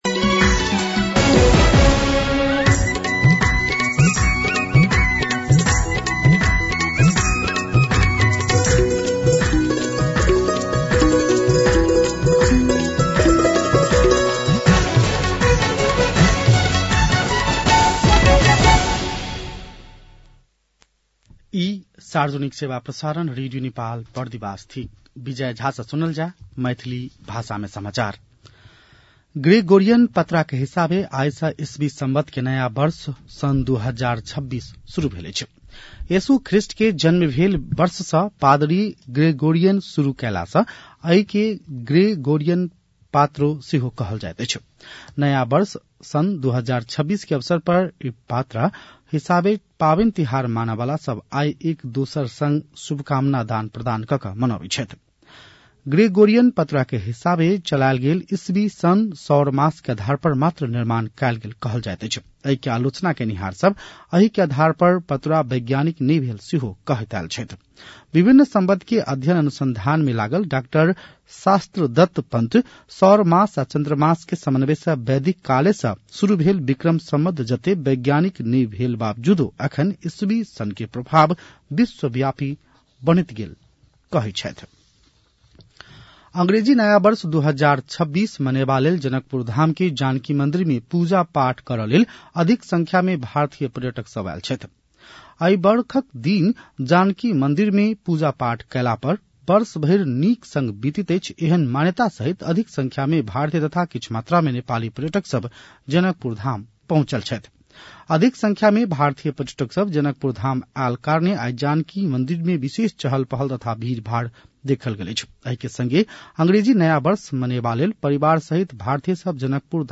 मैथिली भाषामा समाचार : १७ पुष , २०८२
6.-pm-maithali-news-.mp3